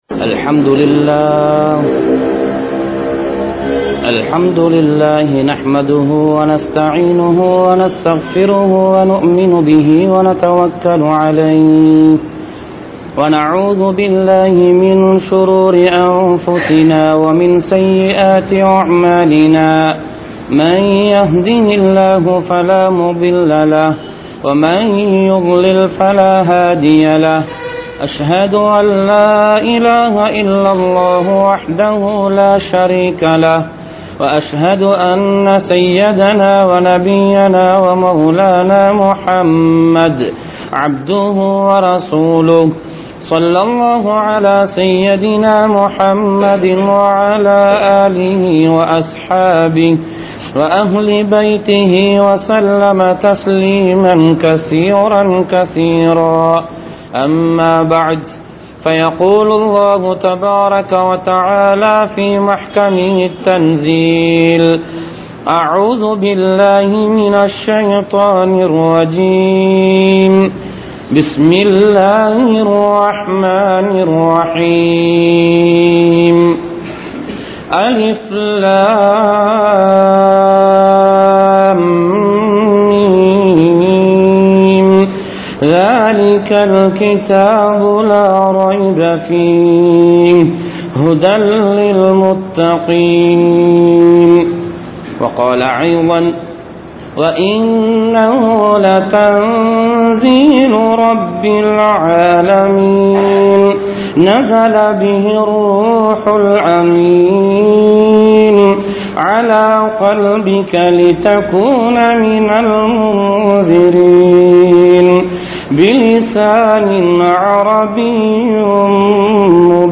Al Quranum Ramalaanum (அல்குர்ஆனும் ரமழானும்) | Audio Bayans | All Ceylon Muslim Youth Community | Addalaichenai
Mallawapitiya Jumua Masjidh